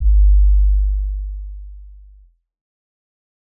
DB - Kick (22).wav